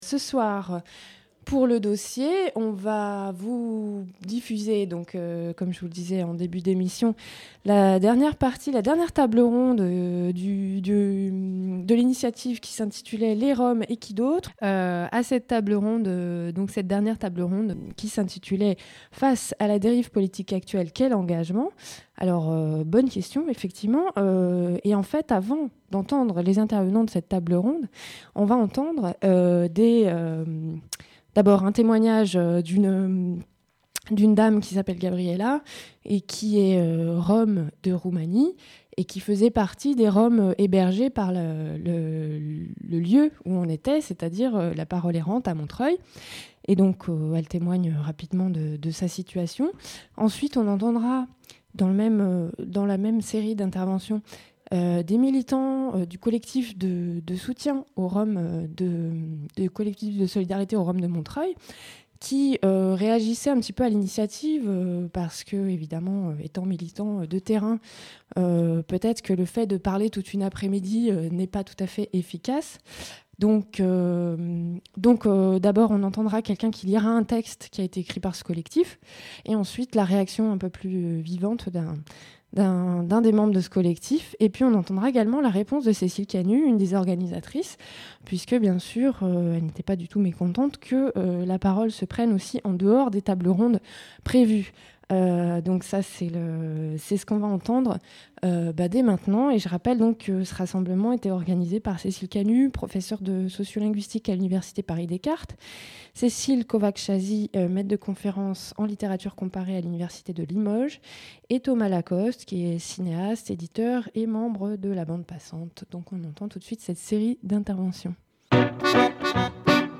lors de la troisième table ronde intitulée Face à la dérive politique actuelle : quel engagement ?